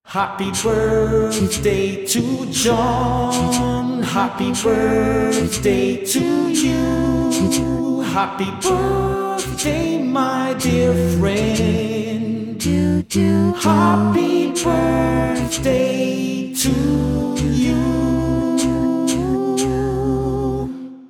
Custom gift-songs